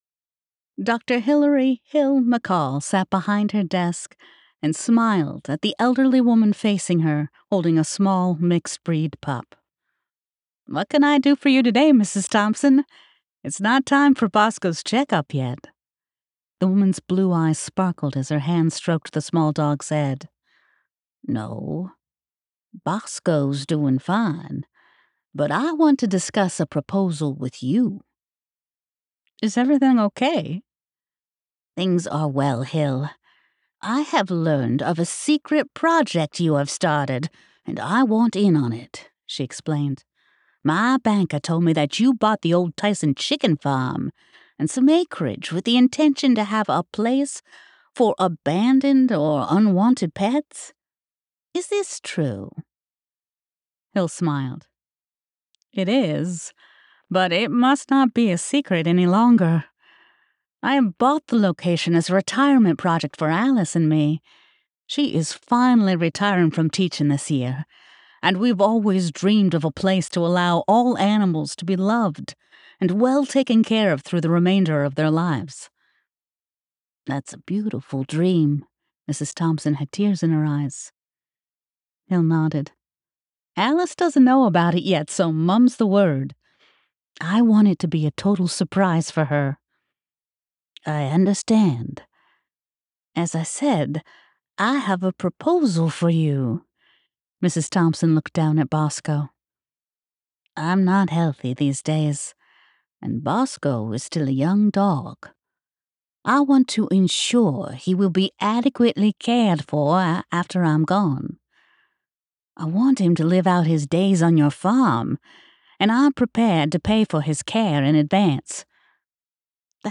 Fairytale Farm by Ali Spooner [Audiobook]